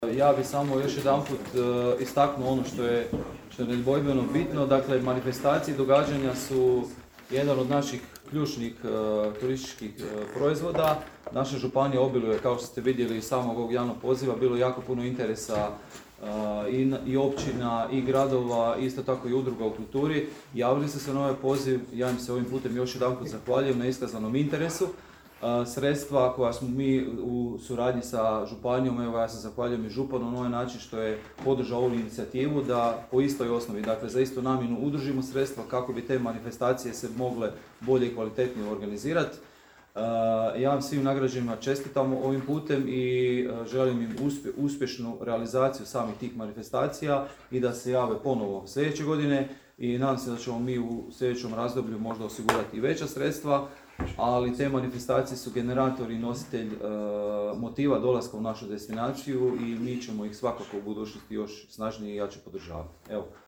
U Bjelovaru je početkom svibnja održano potpisivanje ugovora s prijaviteljima za dodjelu potpora regionalnim i lokalnim događajima u 2022. godini.